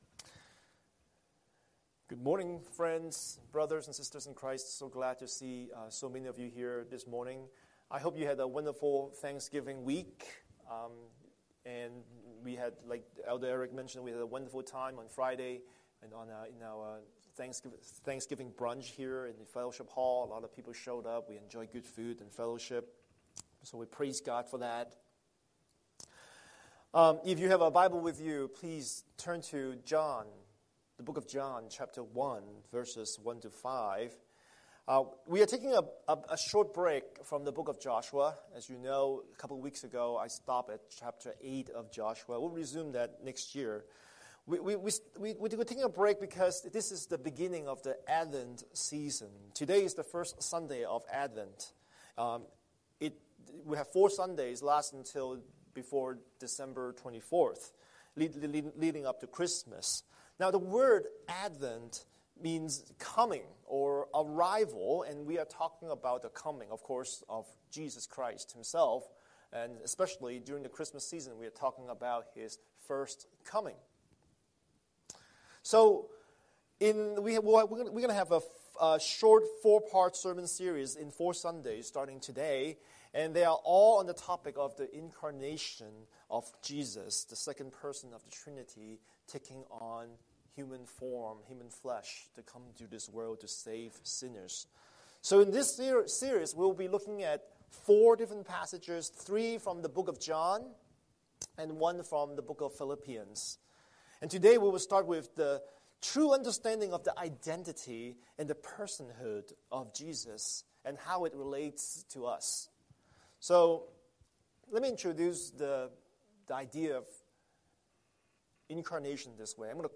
Scripture: John 1:1-5 Series: Sunday Sermon